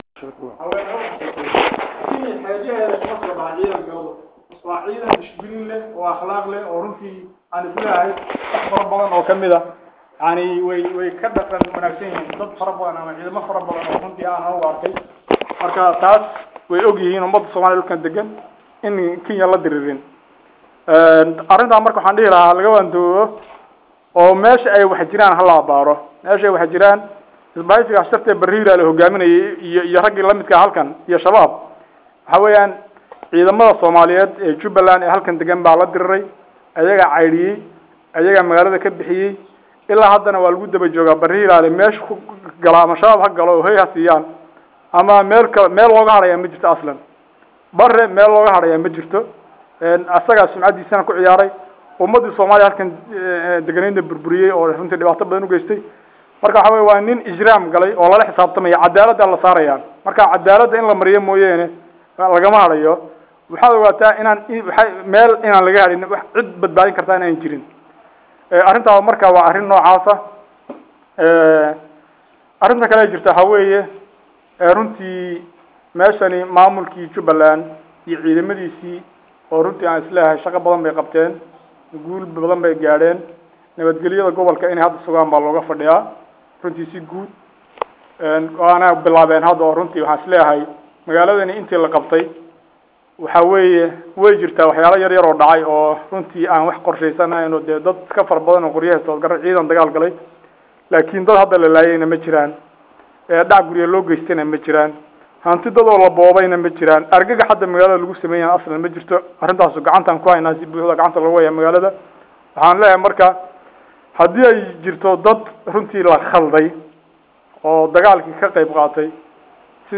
shir jara’id ku qabtay Hoteelka weyn ee loo yaqaan GOLDEN BEACH ( Hotel Qiilmawaaye)
Shirkan Jaraa’id waxa uu ku soo beegmayaa Hadal  ka soo yeeray Bare Hiiraale oo ahaa in aay midowbeen Ayaga iyo Al Shabbaab.